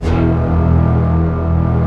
Index of /90_sSampleCDs/Optical Media International - Sonic Images Library/SI1_Fast Strings/SI1_Fast Tutti